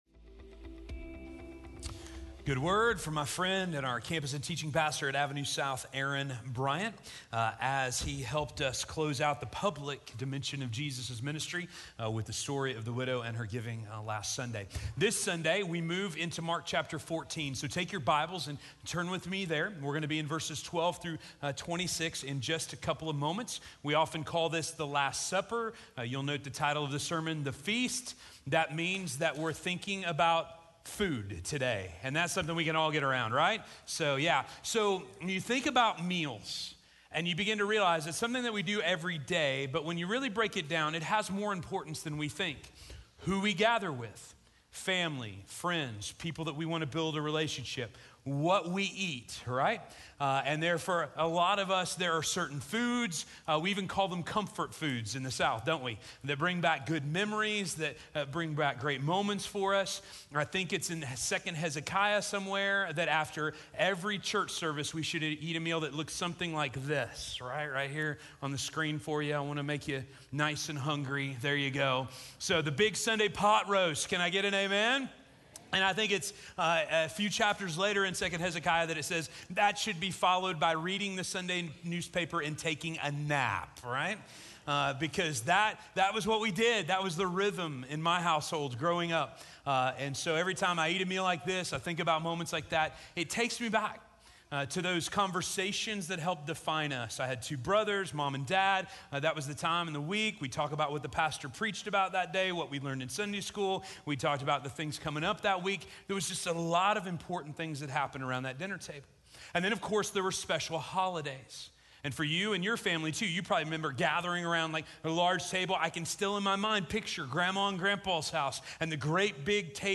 The Feast - Sermon - Station Hill
The_Church_at_Station_Hill_-_April_7__2019_-_Sermon_Only.mp3